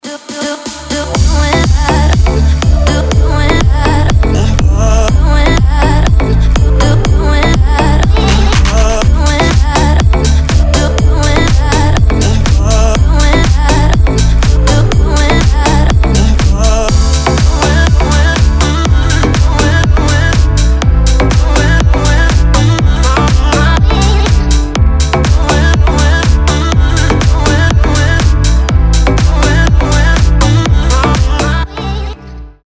• Качество: 320, Stereo
dance
EDM
future house
чувственные
house
Классная танцевальная музыка